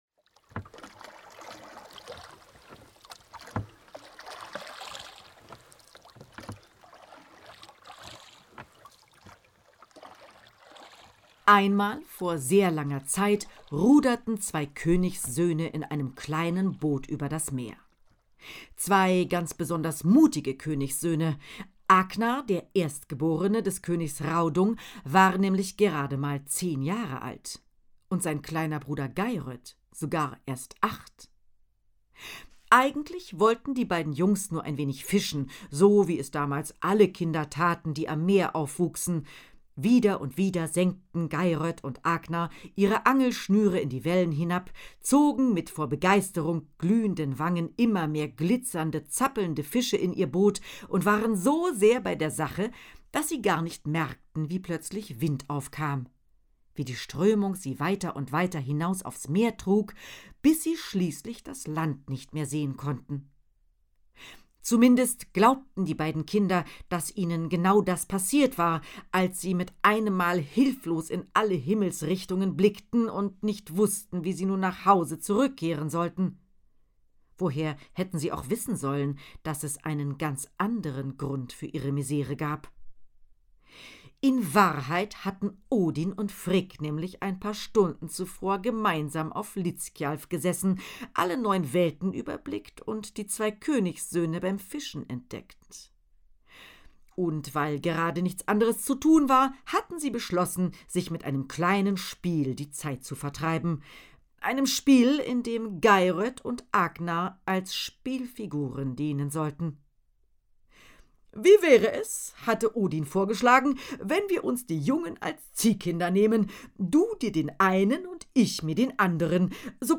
Luci van Org (Sprecher)